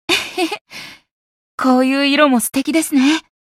觉醒语音 えへへ。